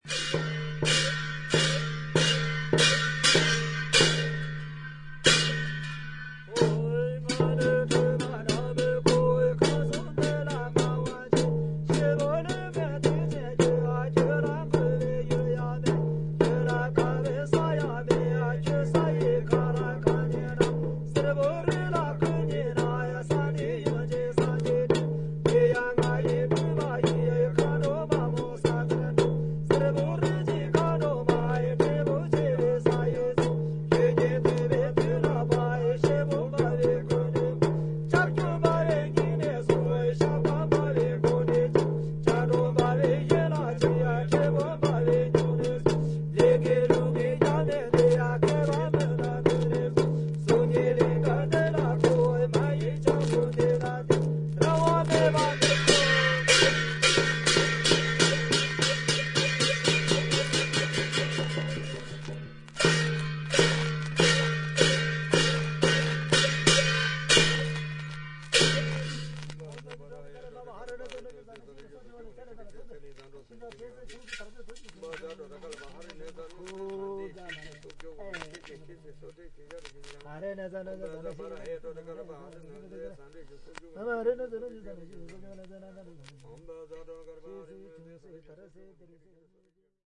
MEDIA : VG＋ ※B面にチリノイズが複数回あり。
インド北部/ヒマーチャル・プラデーシュ州にて録音。肺結核を病んでいる一人のチベット人の為に、一日中行われた悪霊払いの儀式を生々しくレコーディングした作品です。